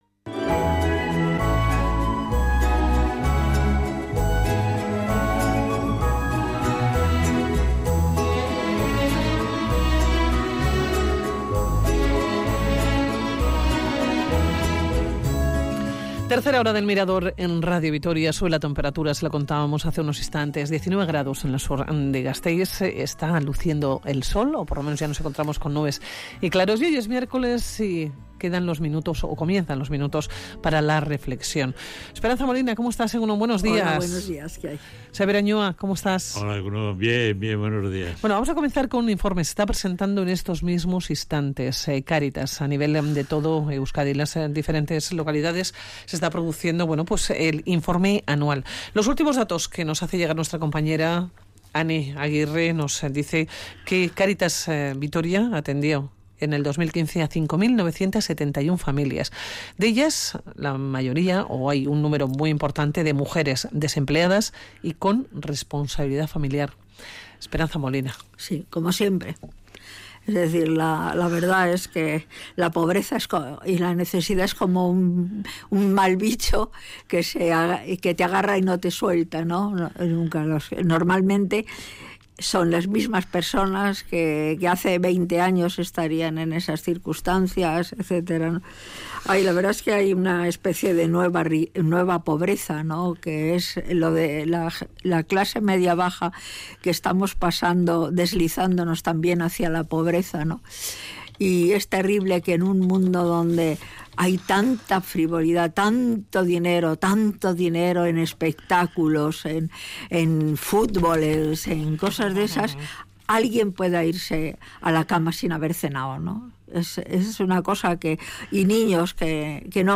Tertulia de Sabios